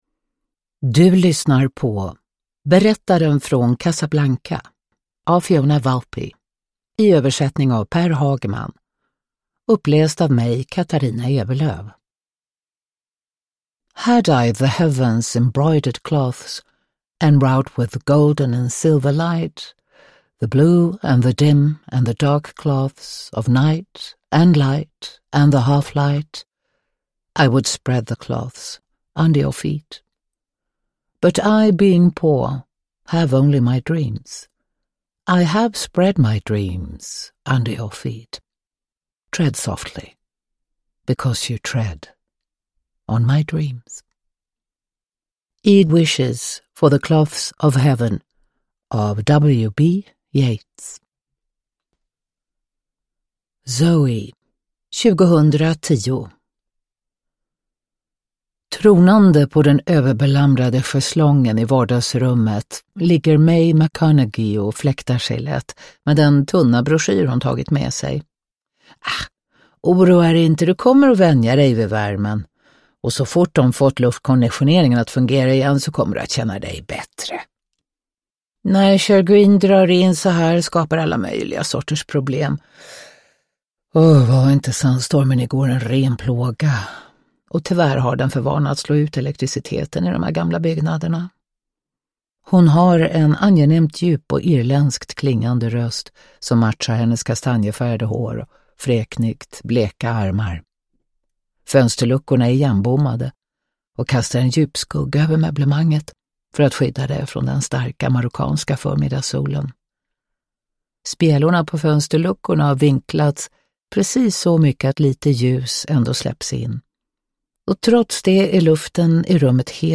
Nedladdningsbar Ljudbok
Ljudbok